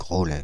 Groslay (French pronunciation: [ɡʁolɛ]
Fr-Paris--Groslay.ogg.mp3